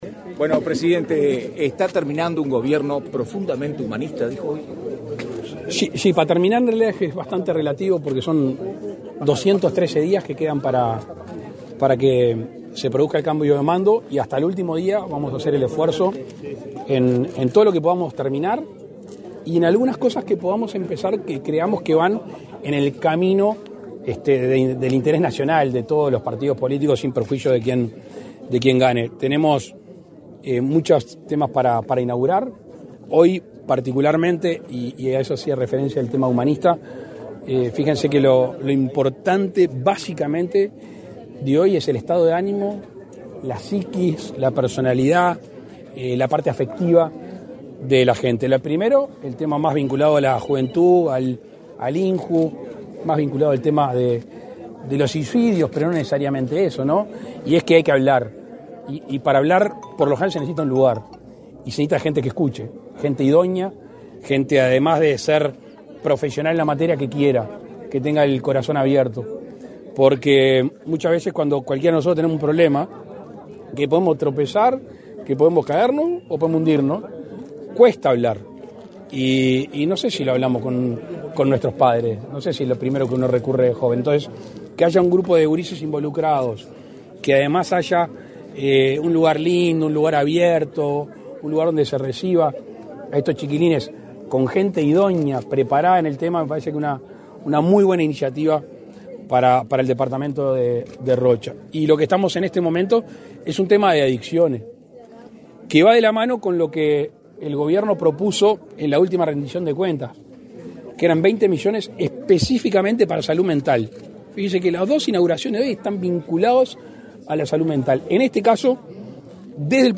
Declaraciones de prensa del presidente de la República, Luis Lacalle Pou
Tras el evento, el mandatario realizó declaraciones a la prensa